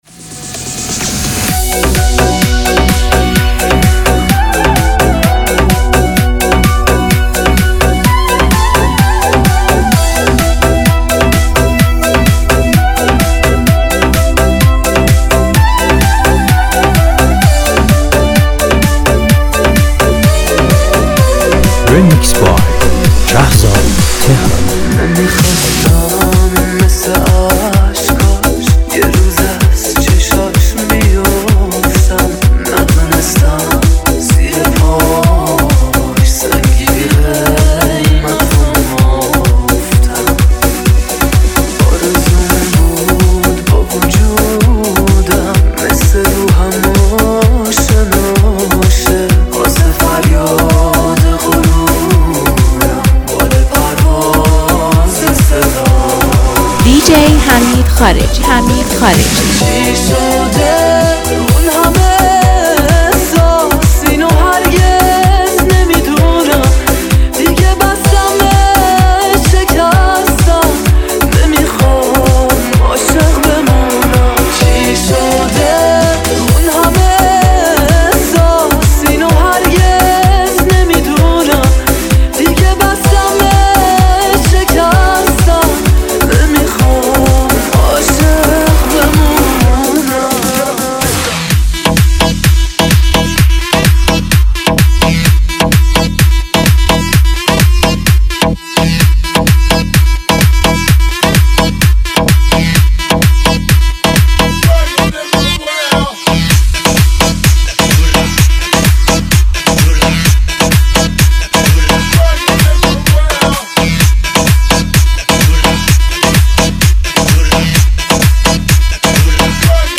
این میکس، با انرژی خاص خود، لحظات شما رو خاطره‌انگیز می‌کنه.